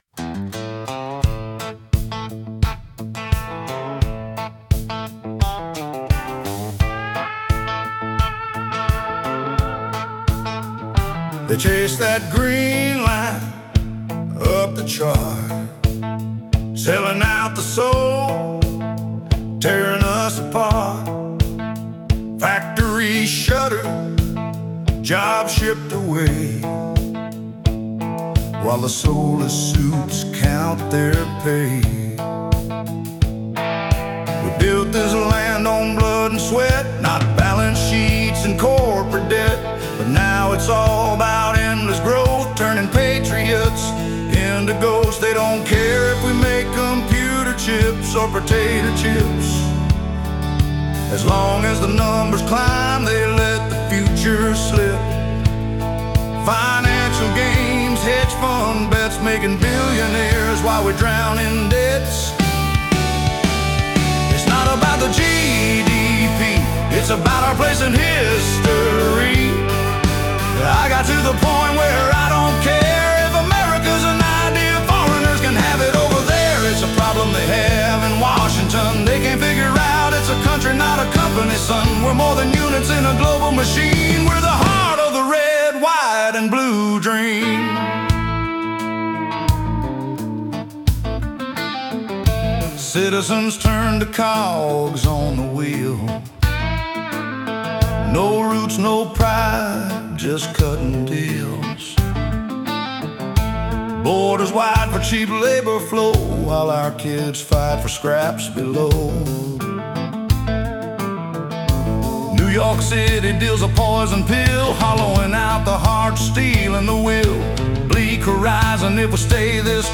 That first version was a country version.